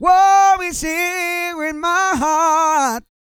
E-GOSPEL 235.wav